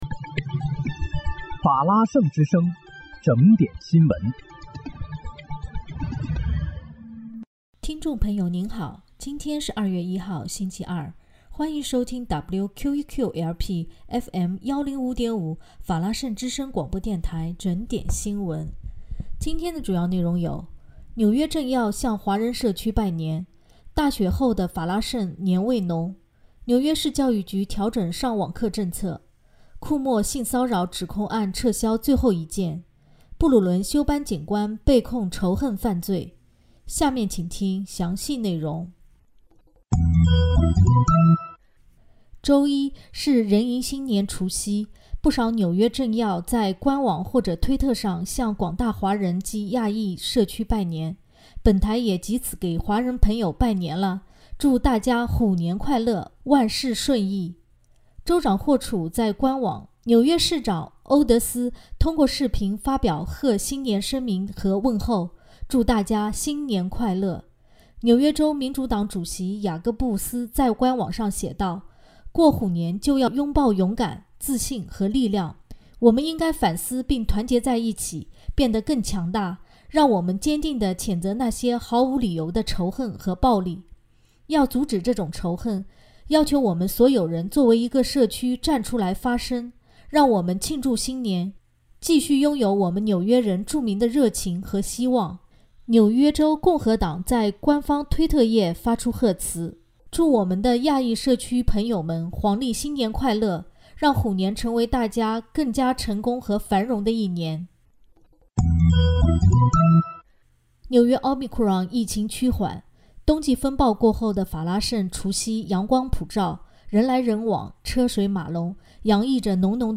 2月1日（星期二）纽约整点新闻